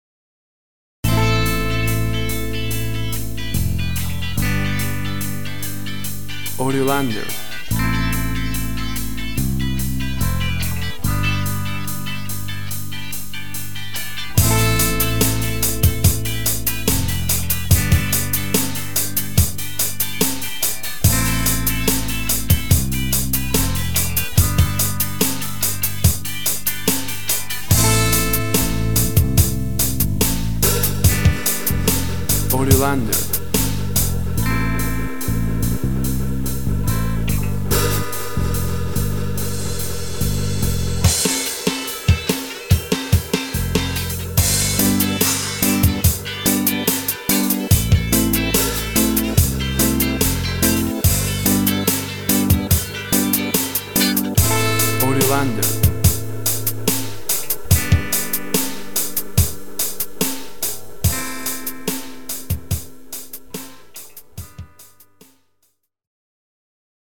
Reggae musical fragment calm, party and good times.
Tempo (BPM) 71